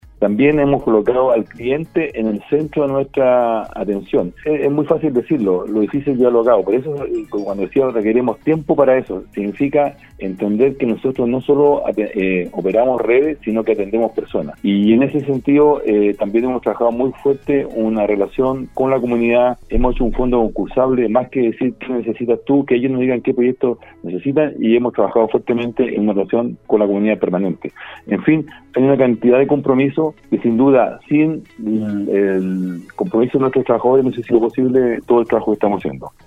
sostuvo un contacto telefónico en el programa Al Día de Nostálgica donde abordó temas de calidad de servicio, cambio de nombre y beneficios para algunos clientes en esta emergencia sanitaria en la que atraviesa el país y a la que Atacama no escapa a esta realidad.